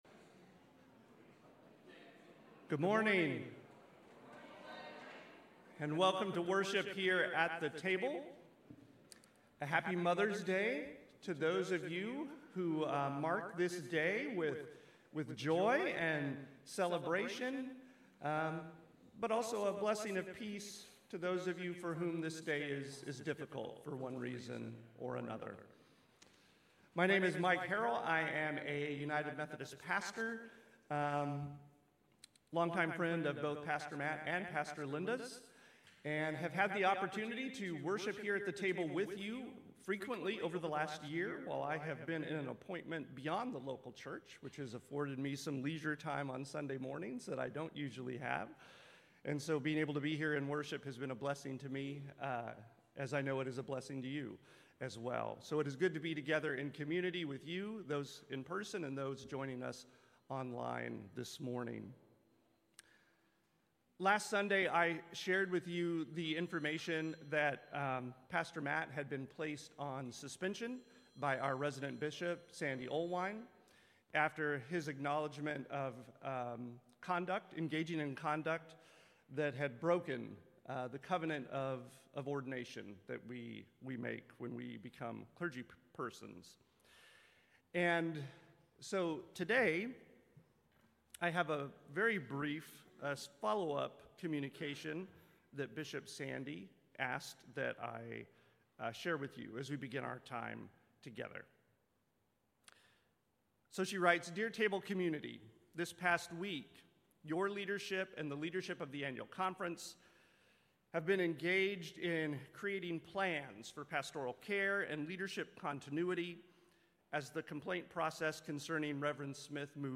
Sermons – The Table UMC podcast